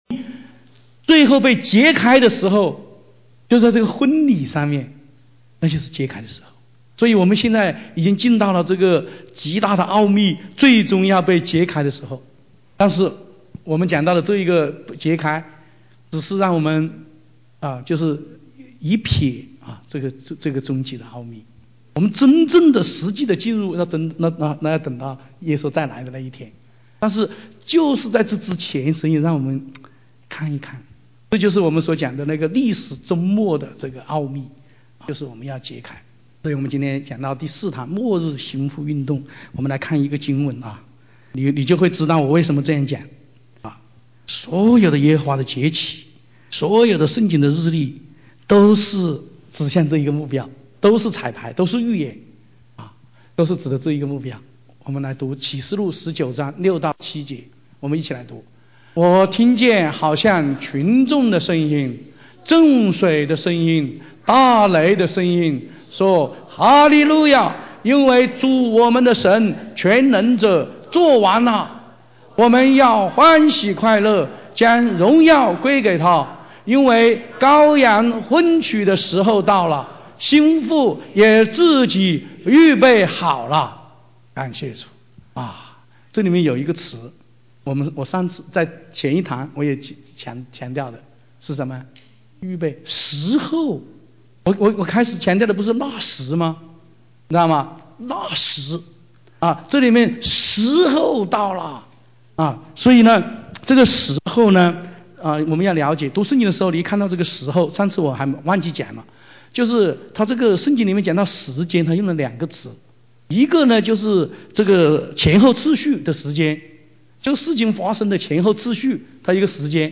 2017温哥华国际新妇特会（7）